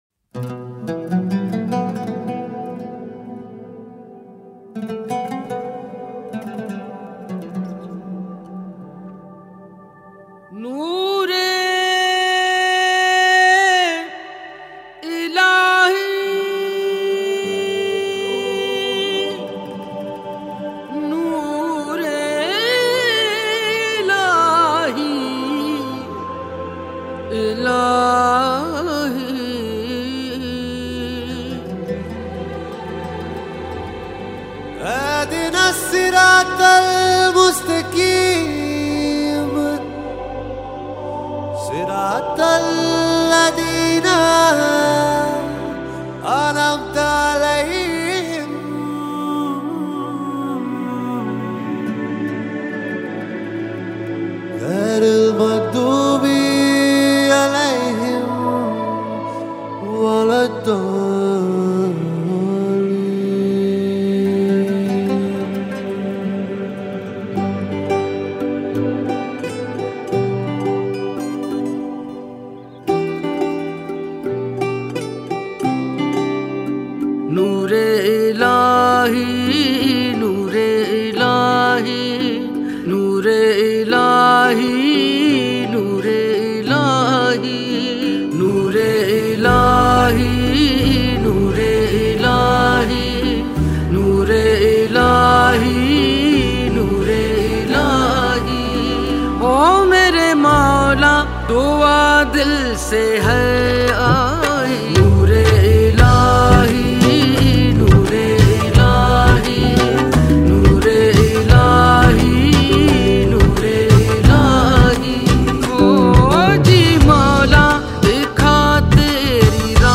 Download 8000+ Sufi MP3 Songs, Books & Art free